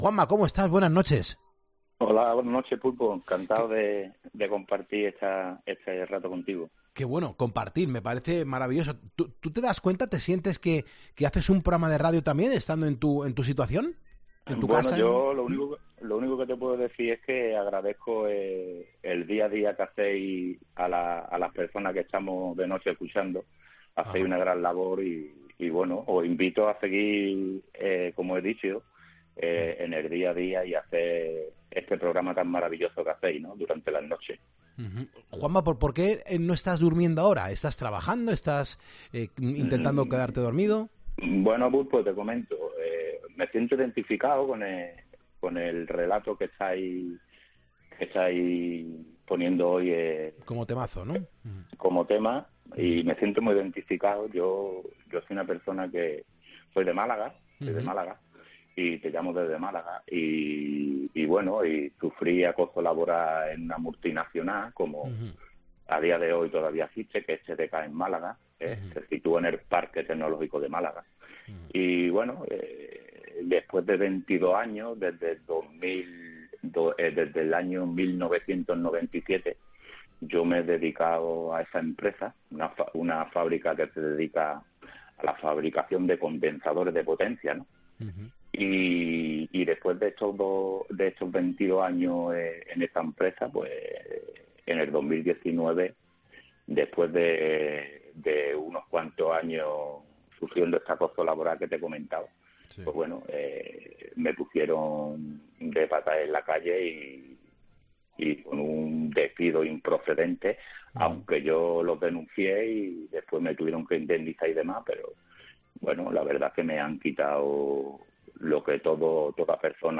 Un trabajador de Málaga explica su caso de acoso laboral que acabó en despido improcedente: "Dediqué 22 años"